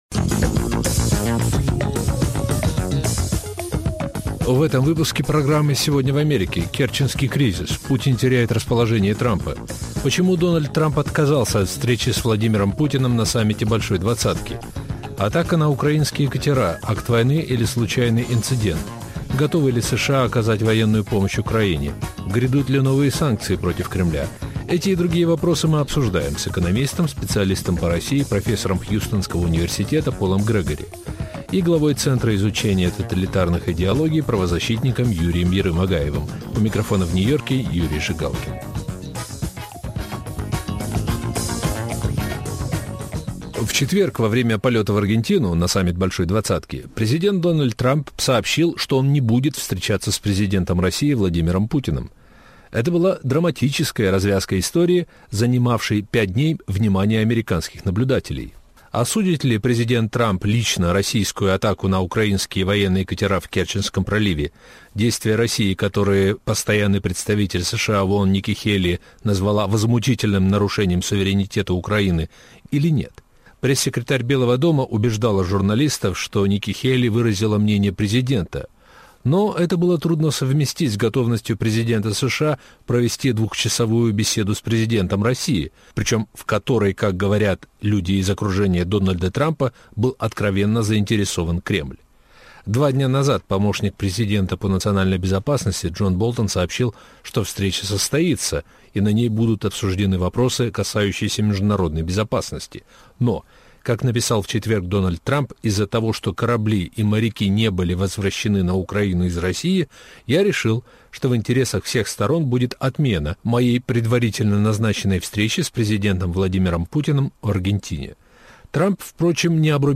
Эти и другие вопросы мы обсуждаем с экономистом специалистом по России